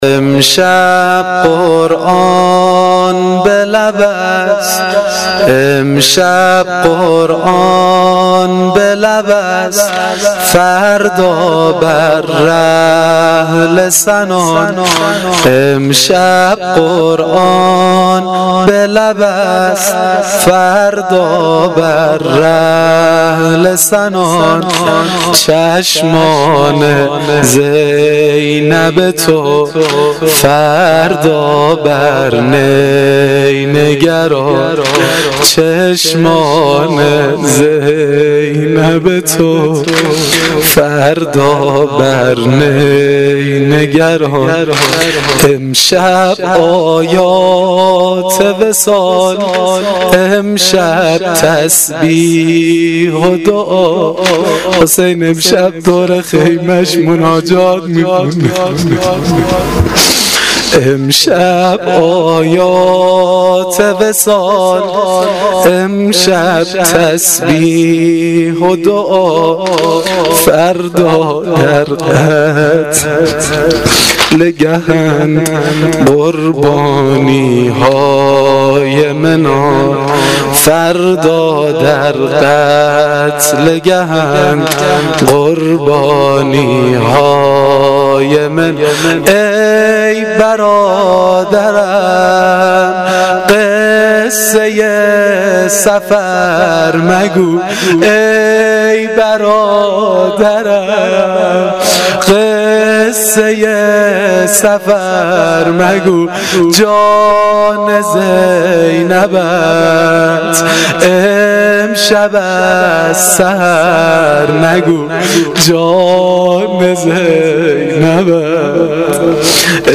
واحد سنگین شب دهم محرم الحرام 1396 (شب عاشورا)
• هیئت جواد الائمه
واحد سنگین, روضه